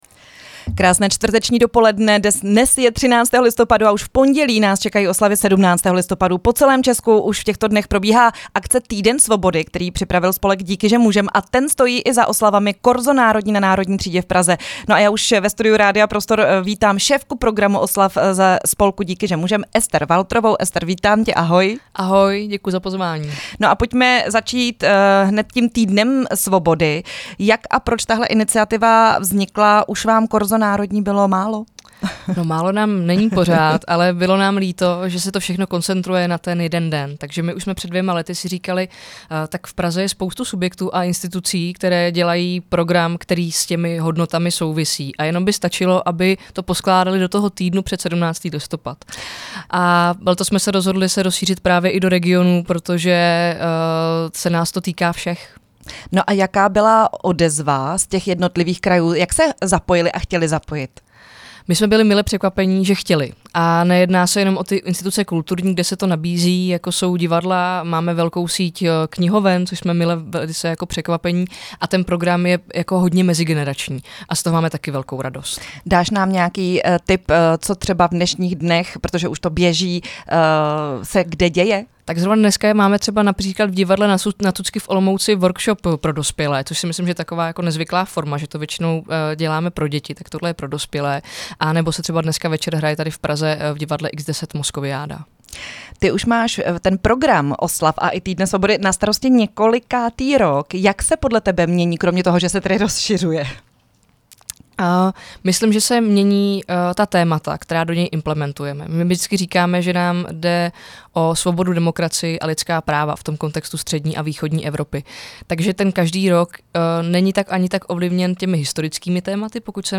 Rozhovory
Host Rádia Prostor